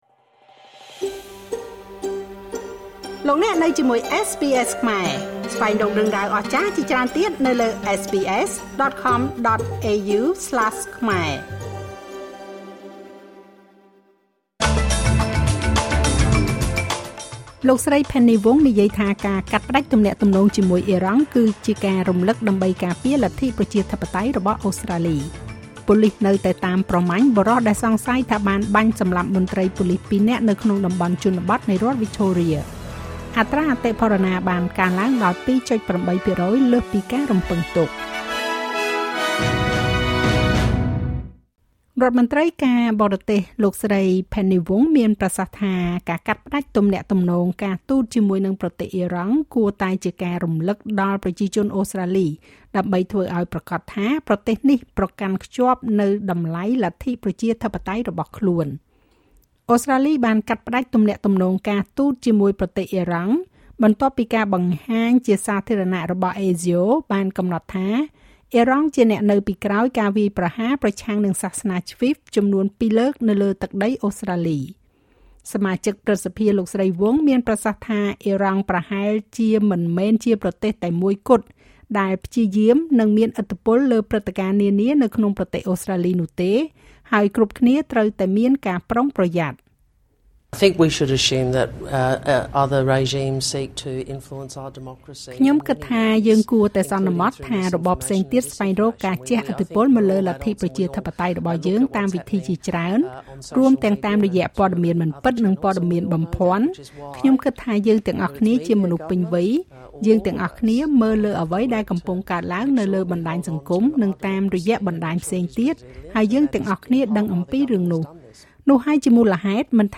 នាទីព័ត៌មានរបស់SBSខ្មែរ សម្រាប់ថ្ងៃពុធ ទី២៧ ខែសីហា ឆ្នាំ២០២៥